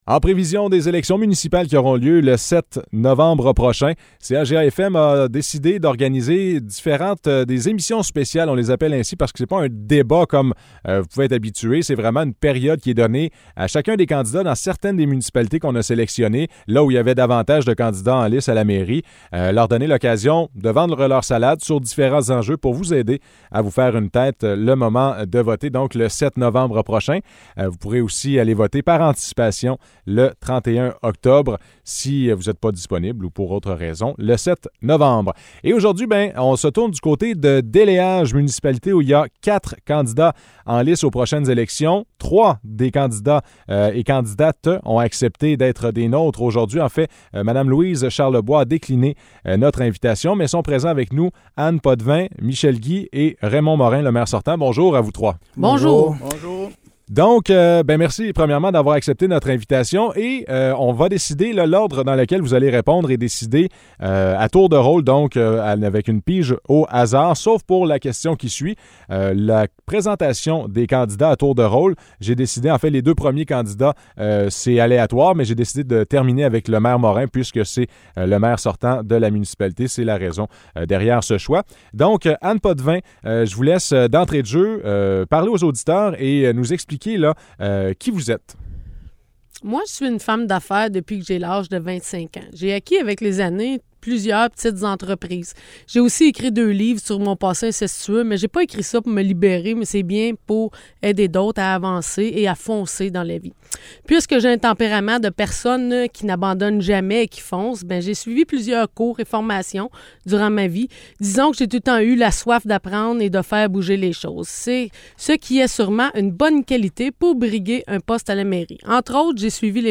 Entrevue avec les candidats à la mairie de Déléage
Apprenez-en plus sur les idées et projets des candidats et candidates aspirant à la mairie de Déléage. Trois d'entre eux ont accepté l'invitation de CHGA pour cette émission spéciale de l'Heure juste.